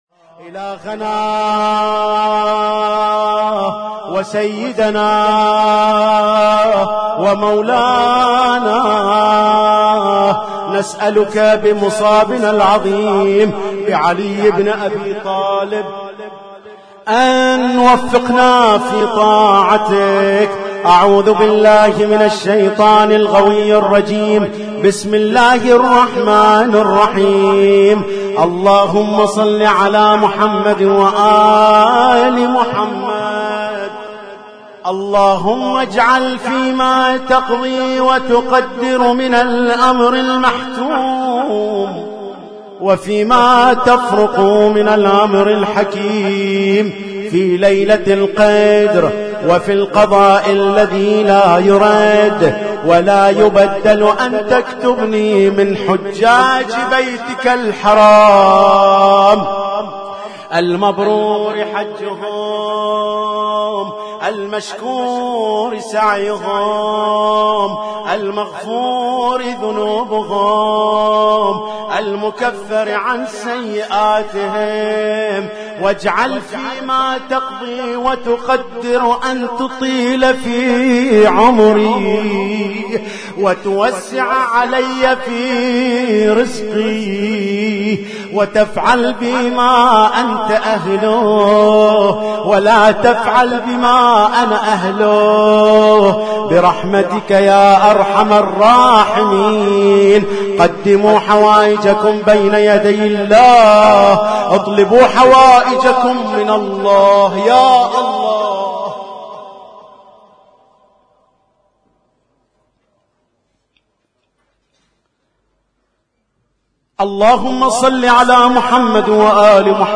اسم التصنيف: المـكتبة الصــوتيه >> الادعية >> ادعية ليالي القدر